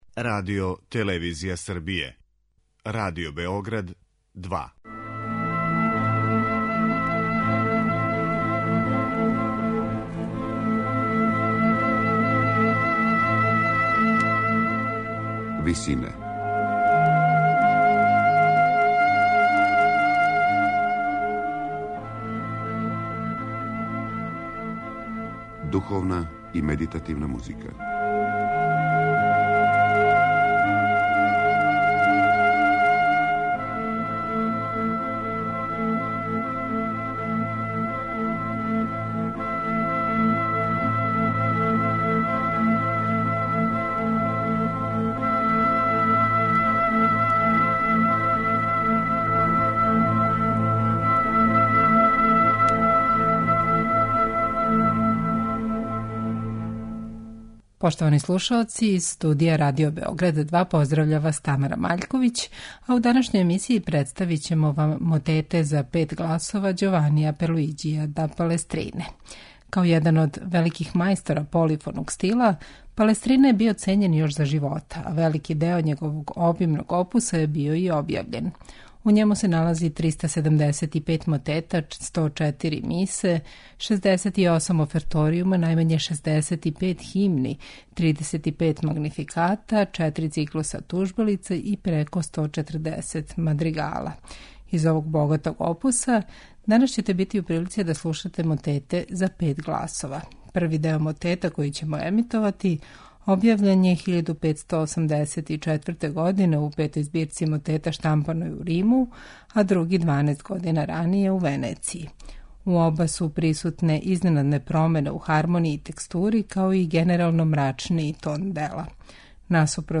Петогласни мотети Ђованија Пиерлуиђија да Палестрине
У емисији Висине представљамо вам петогласне мотете Ђованија Пиерлуиђија да Палестрине, једног од највећих мајстора ренесансне полифоније. Слушаћете интерпретације ансамбла Хилијард.